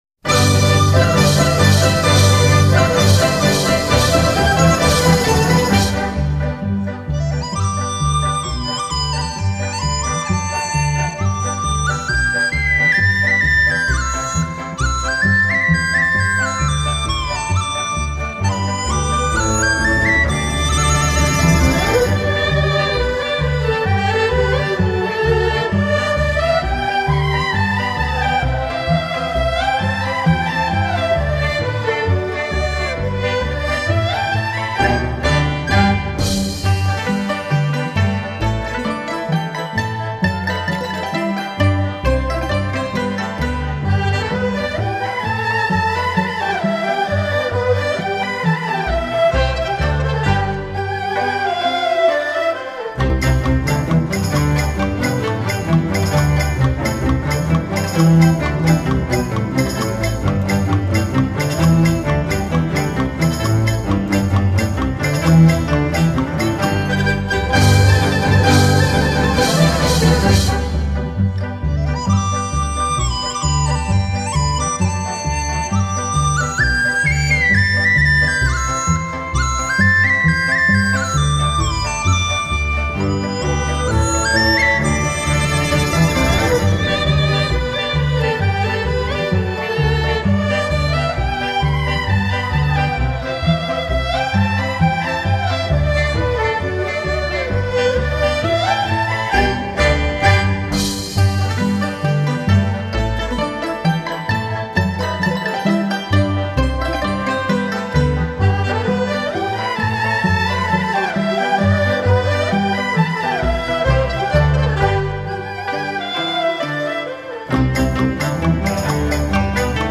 意，优美动人的旋律让你一次次陶醉、回味无穷！